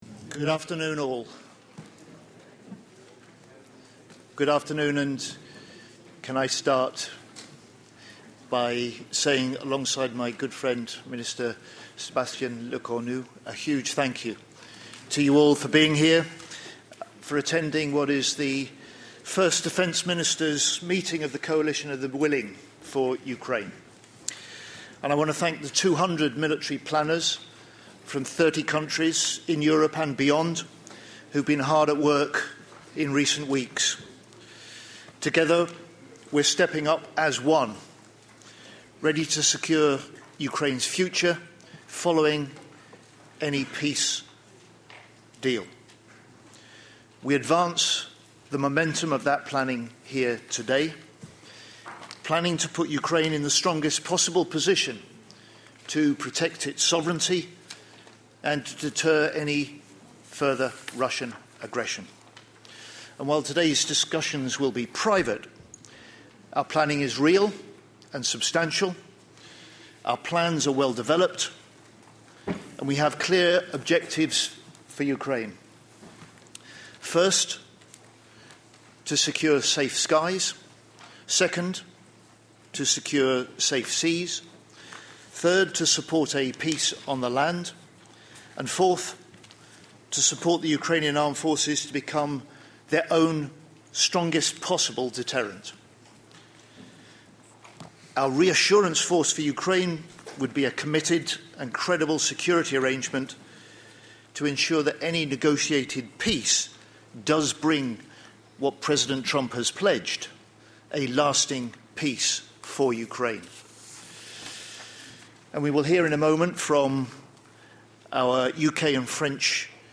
ENGLISH - Opening remarks by UK Secretary of State for Defence John Healey at the Meeting of the Coalition of the Willing